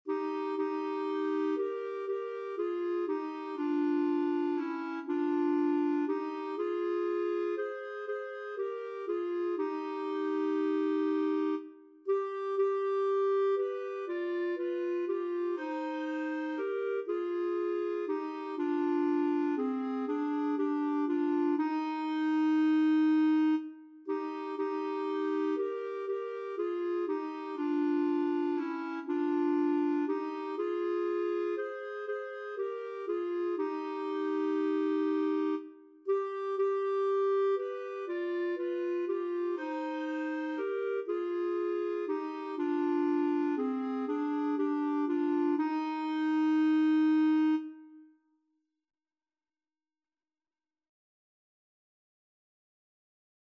Title: Jesus num berço de palhas Composer: Pedro Sinzig Lyricist: Durval de Moraiscreate page Number of voices: 2vv Voicing: SA Genre: Sacred, Chant
Language: Portuguese Instruments: Organ